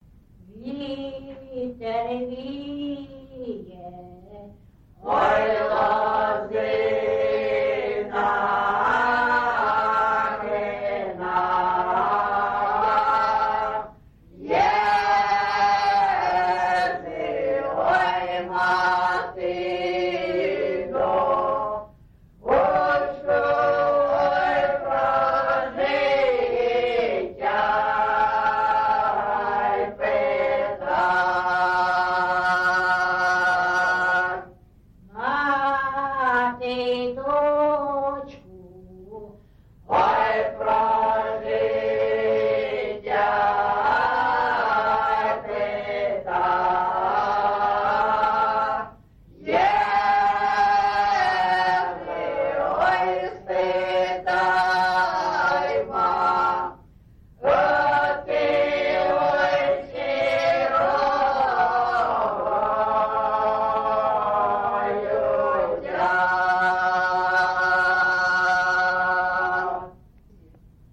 Recording locationAndriivka, Velykа Novosilka district, Donetsk obl., Ukraine, Sloboda Ukraine